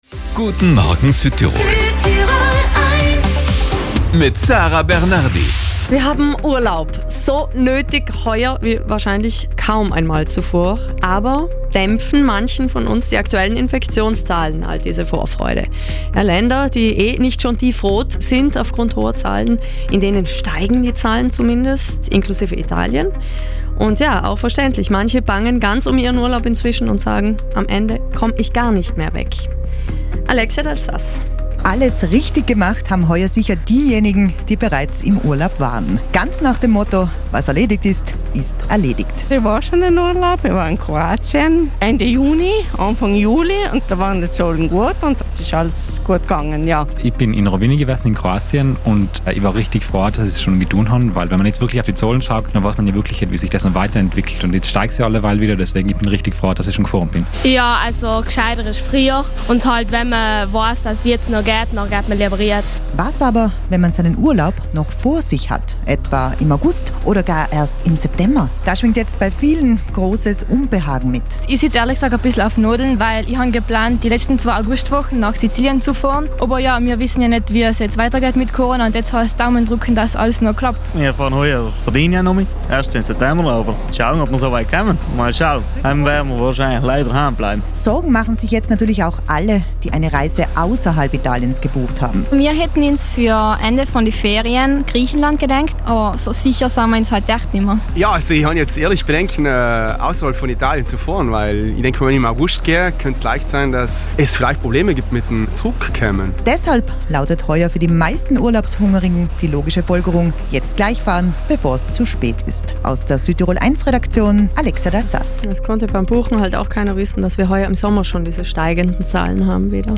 Viele, die ihren Urlaub erst Ende August, Anfang September geplant haben, bangen nun darum und hoffen überhaupt noch fahren zu können. Wie sehr sich bei den Südtirolern schon die Urlaubs Torschlusspanik breit macht, wir haben uns umgehört.